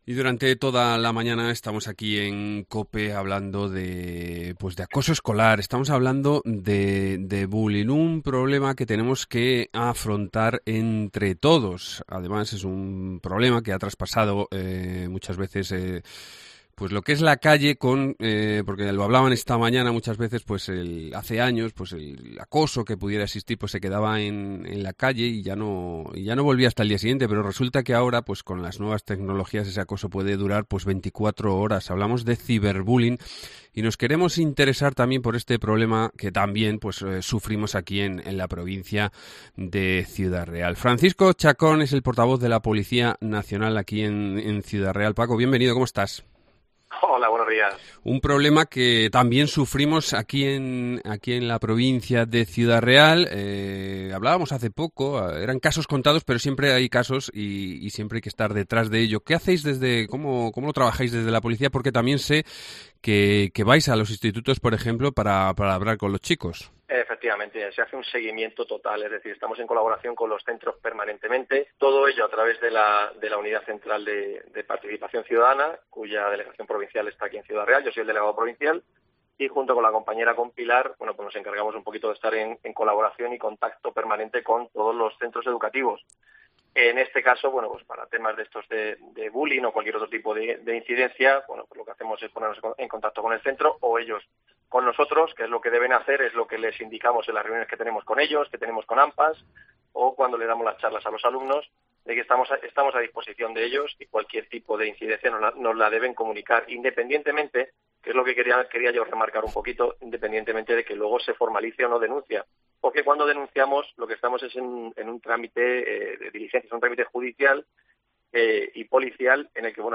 Entrevista/Bullying